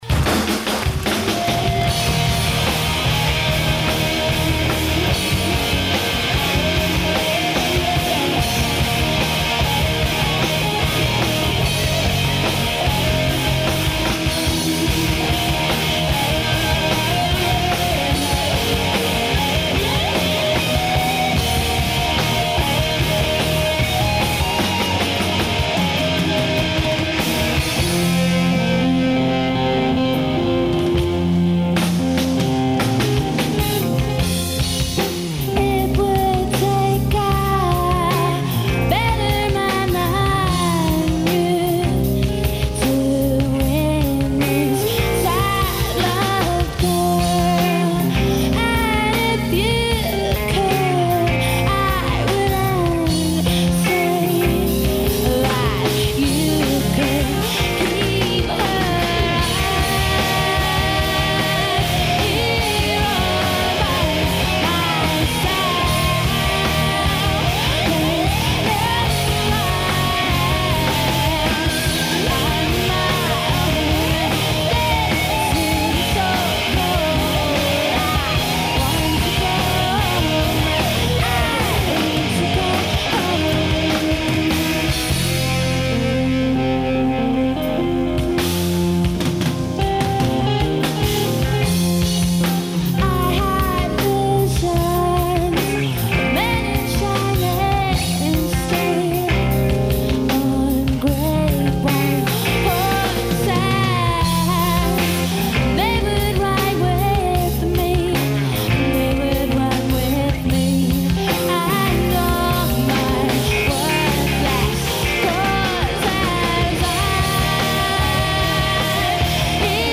enregistrée le 28/05/1996  au Studio 105